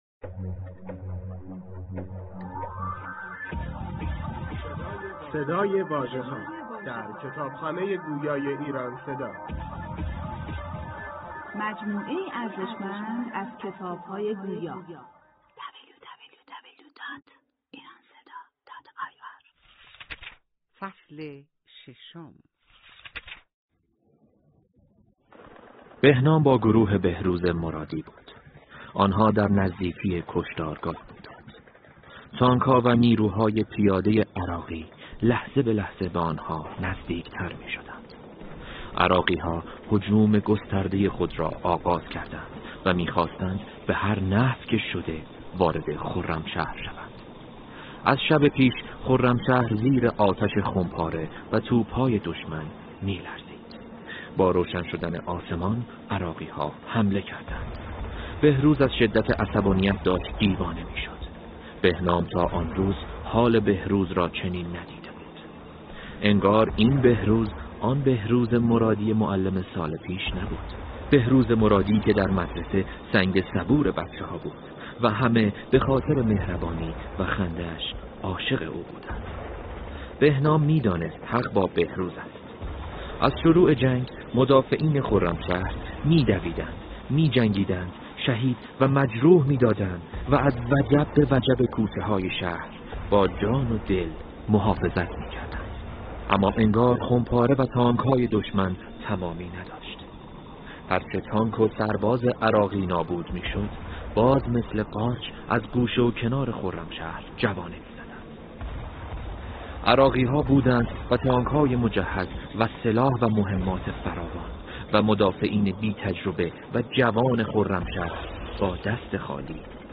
به کوشش «ایران صدا» کتاب «داستان بهنام» در قالب 6 فصل و 237 دقیقه برای مخاطبان به فایل صوتی تبدیل و ارائه شده است.